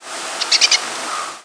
Pine Siskin Carduelis pinus
Flight call description A quick, harsh "chu-tu-tu-tu", a strident, emphatic "klee-you", and a rising, nasal "waaaee" are the most common flight calls.
"Chu-tu-tu-tu" call from bird in flight.